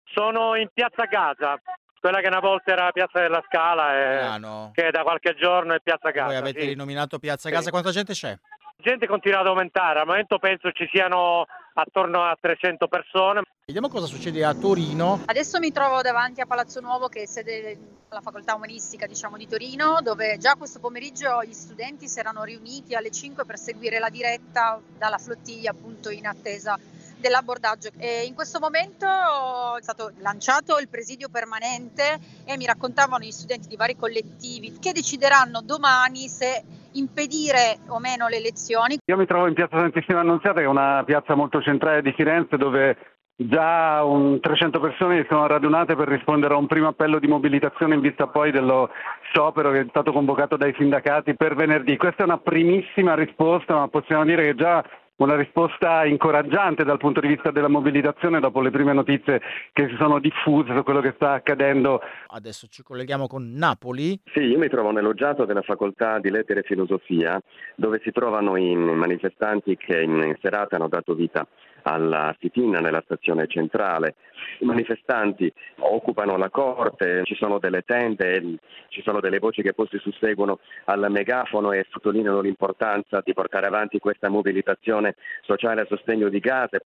Ascoltiamo  le voci dei manifestanti e dei nostri inviati raccolte ieri sera durante la diretta di Radio Popolare.